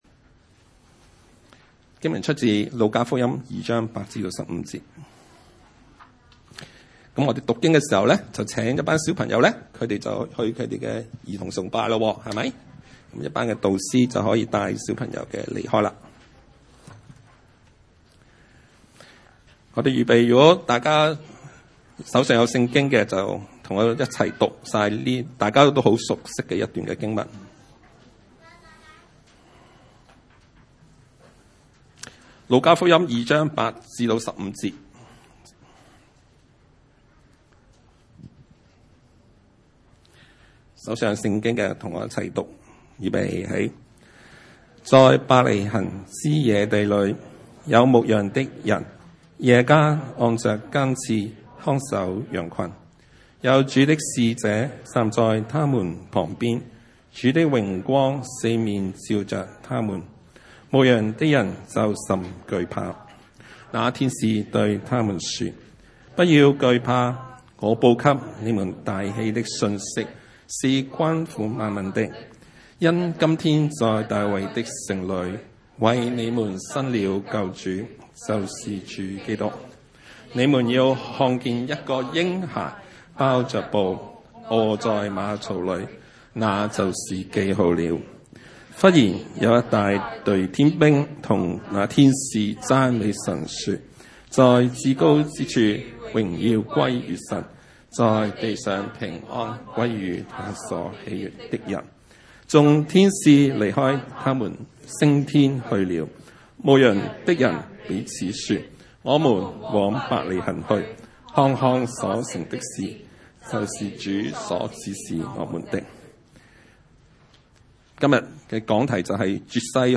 路二章08-15節 崇拜類別: 主日午堂崇拜 8 在伯利恆之野地裡有牧羊的人，夜間按著更次看守羊群。